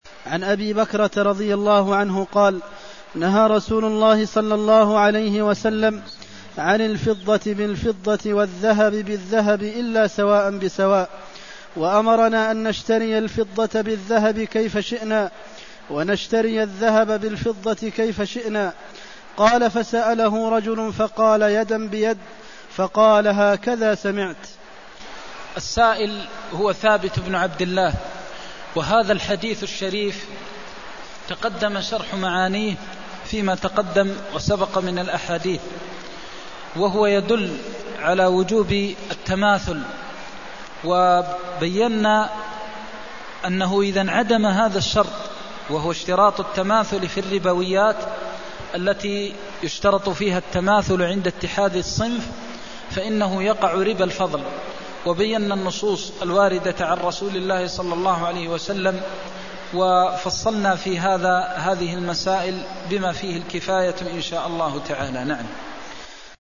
المكان: المسجد النبوي الشيخ: فضيلة الشيخ د. محمد بن محمد المختار فضيلة الشيخ د. محمد بن محمد المختار نهى عن الفضة بالفضة والذهب بالذهب إلا سواء بسواء (264)